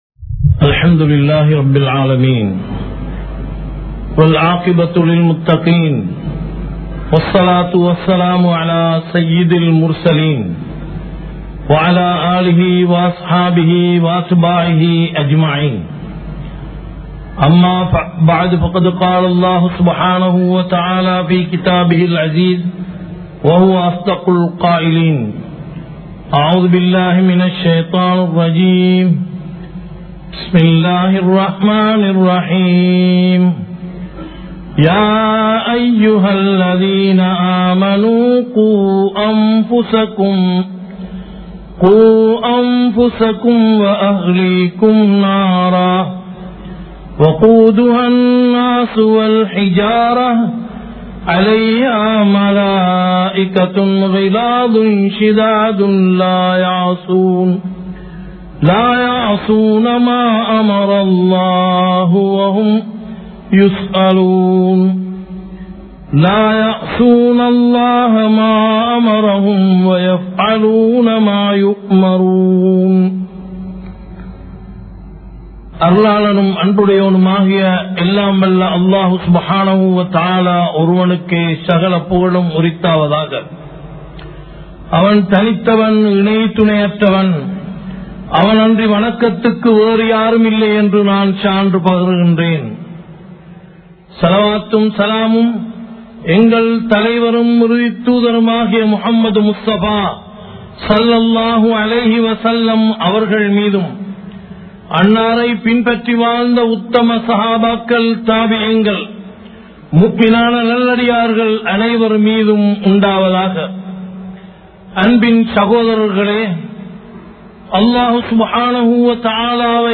Kulanthaikal Valarpil Petrorkalin Poruppukkal(குழந்தைகள் வளர்ப்பில் பெற்றோர்களின் பொறுப்புக்கள்) | Audio Bayans | All Ceylon Muslim Youth Community | Addalaichenai
Kollupitty Jumua Masjith